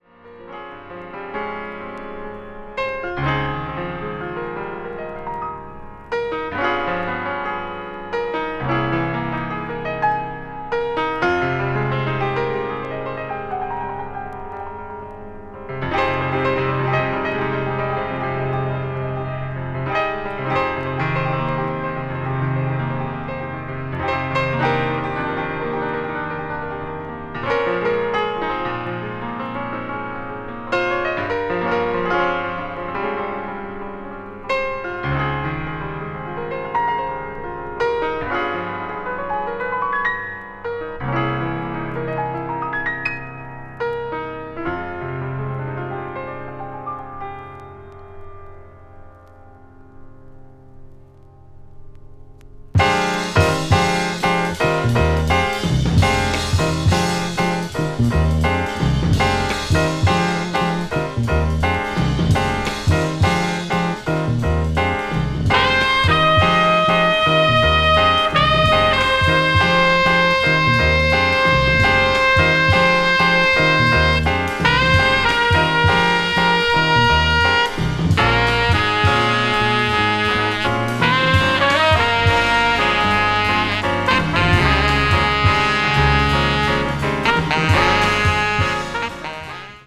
modal jazz   post bop   spritual jazz